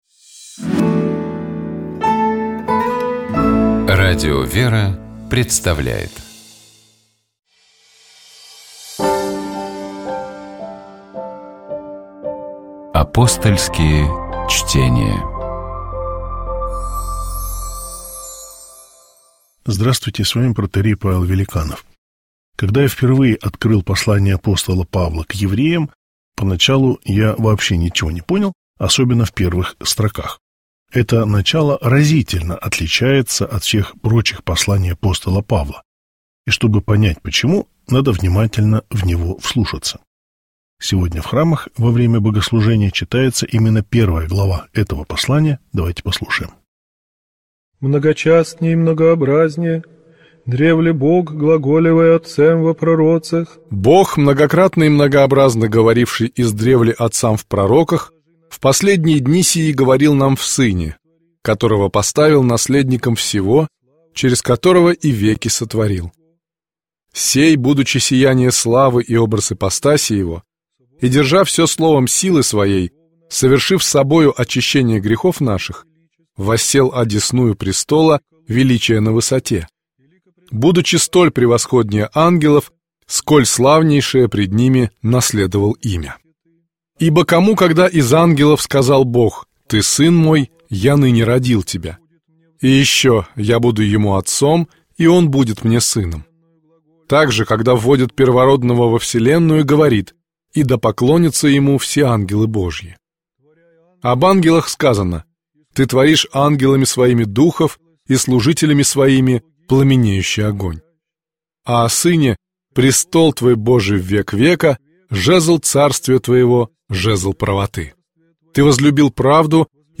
ГлавнаяПрограммыАпостольские чтения